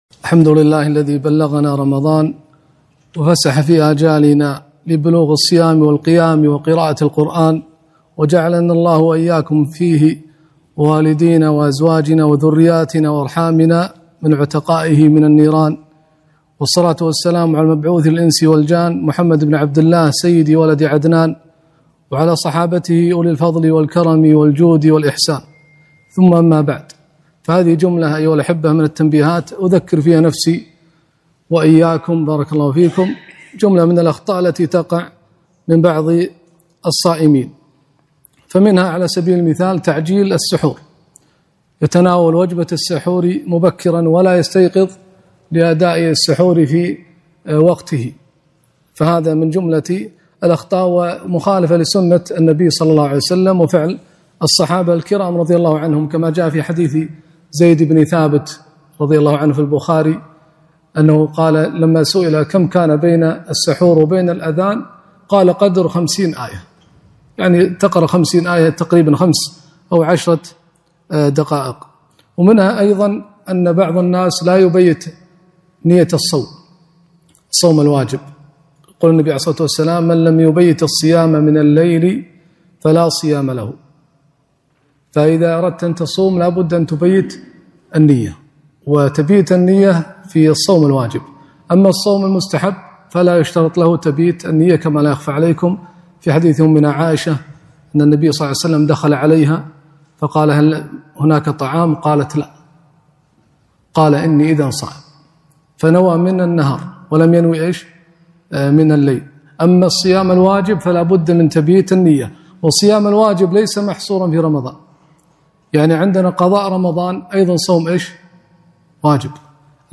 كلمة - مخالفات في شهر رمضان